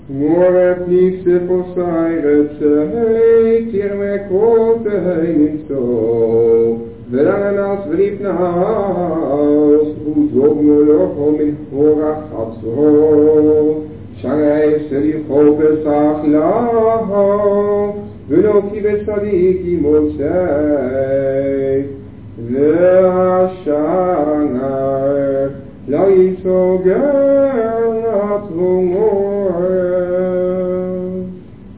op cassettebandjes